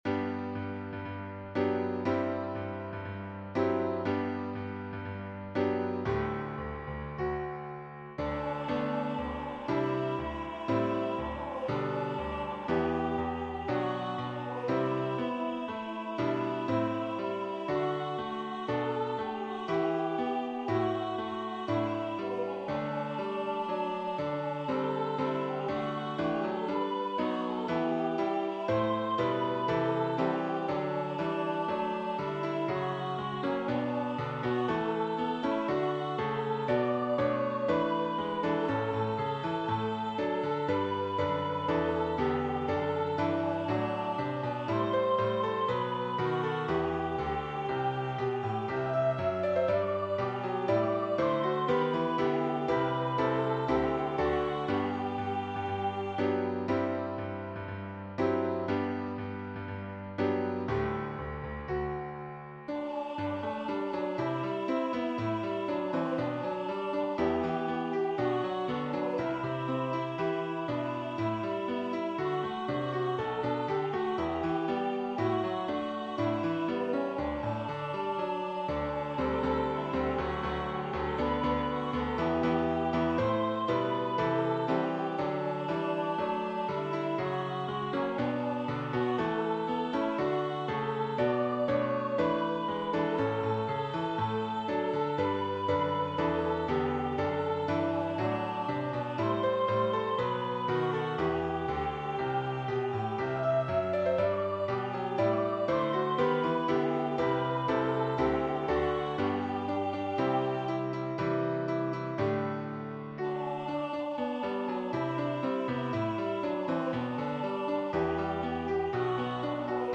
Begins with choir and piano and adds organ and congregation.